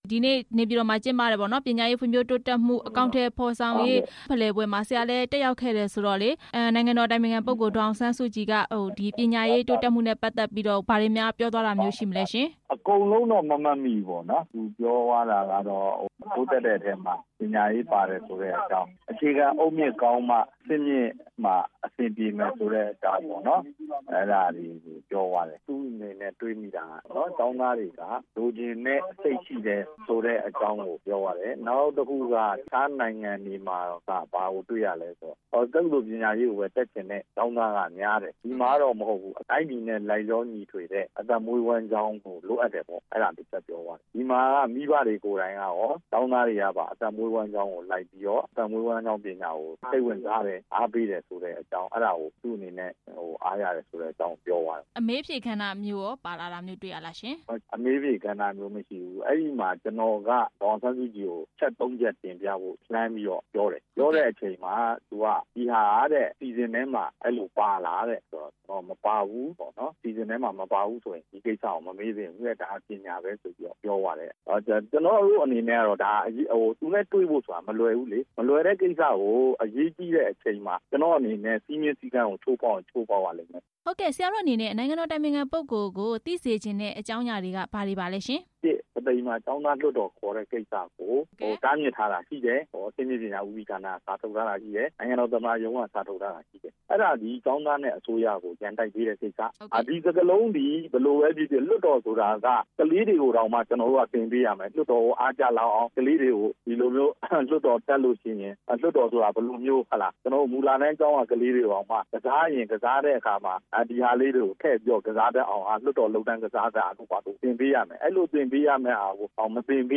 မေးမြန်းခန်း